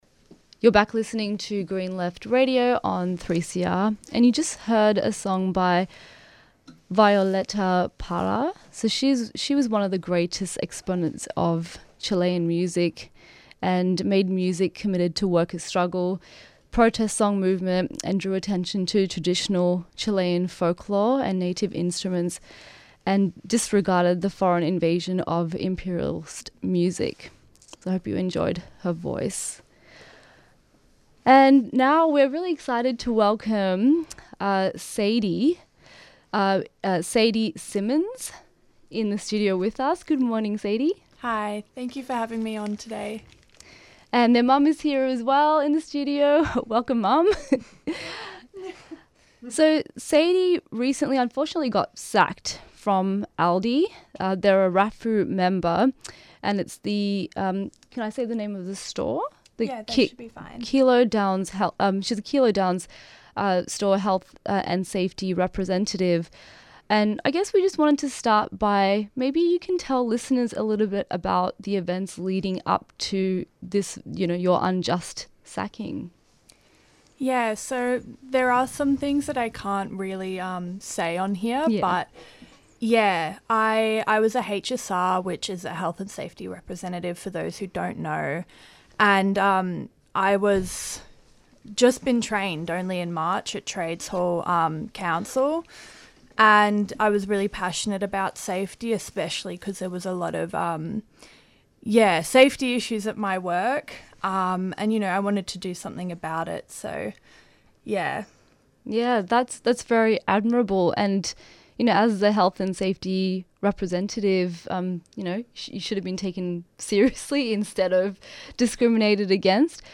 Newsreports Presenters discuss the points raised in Labor must abandon AUKUS now published on Green Left and comment on Albanese's recent visit to China.
Interviews and Discussion